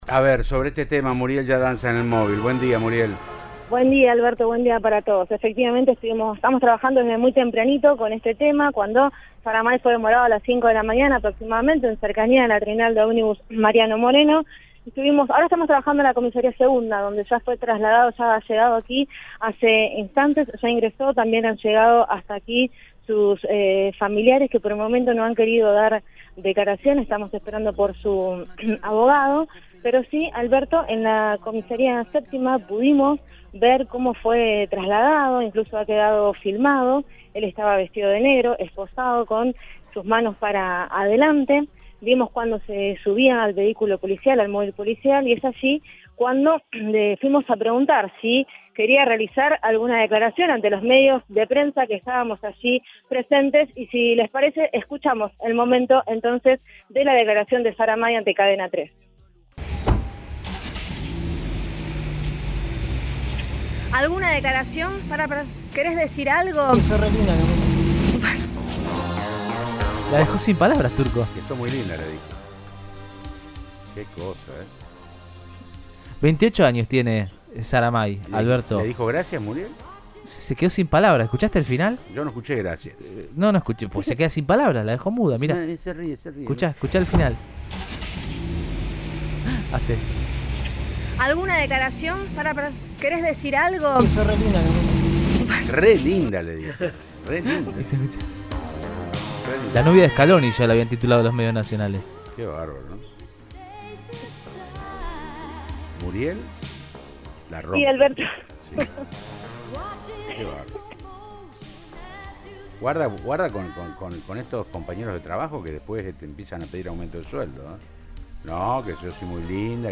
Desde temprano en el lugar, el móvil de Cadena 3 Rosario contó que ningún familiar quiso hablar sobre la situación de Zaramay.
Sin embargo, ante la insistencia de la movilera de la radio, le dijo: "Sos re linda".